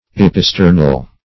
Search Result for " episternal" : The Collaborative International Dictionary of English v.0.48: Episternal \Ep`i*ster"nal\, a. (Anat.
episternal.mp3